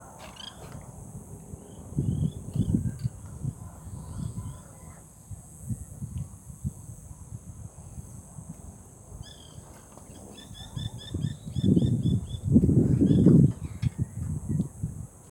Carpintero Blanco (Melanerpes candidus)
Nombre en inglés: White Woodpecker
Localización detallada: Reserva de Usos Múltiples "el Guayabo"
Condición: Silvestre
Certeza: Observada, Vocalización Grabada
Carpintero-blanco_1.mp3